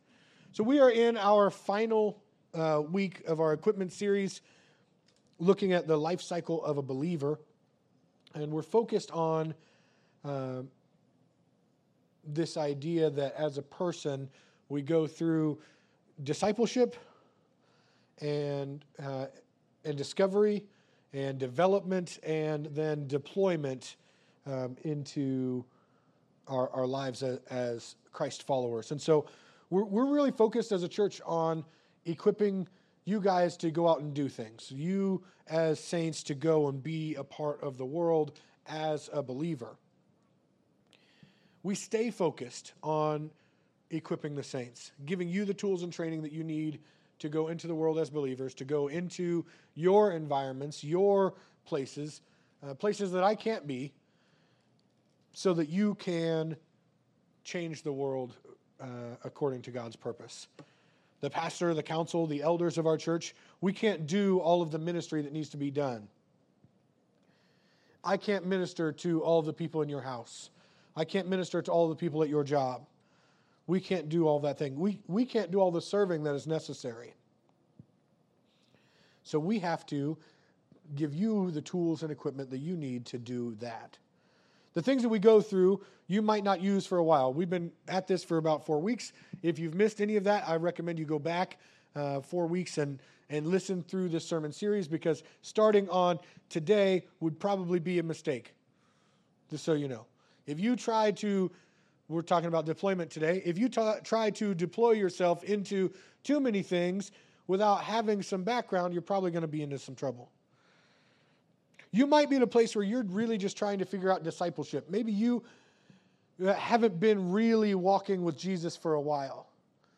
Sermons | Calvary Foursquare Church